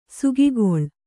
♪ sugigoḷ